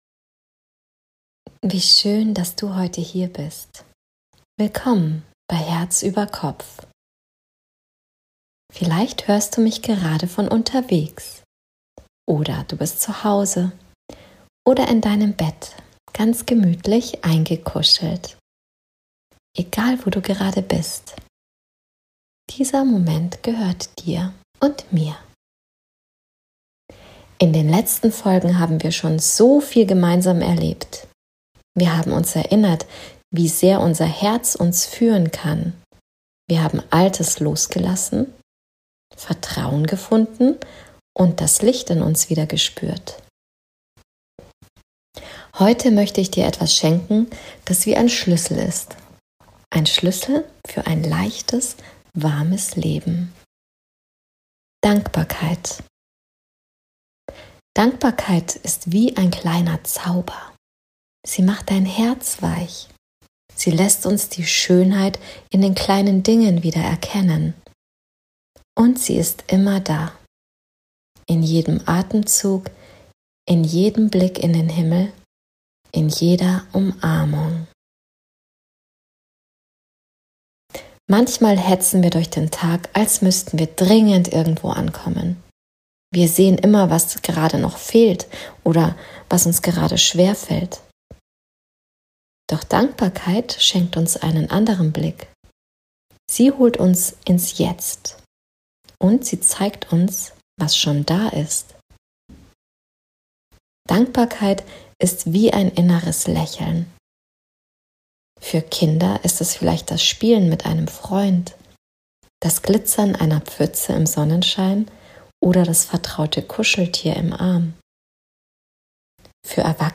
In dieser geführten Herzreise & Meditation öffnest du dein Herz, lässt Leichtigkeit und Freude in dein Leben fließen und findest Momente der Ruhe, die dich im Alltag stärken.